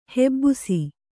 ♪ hebbusi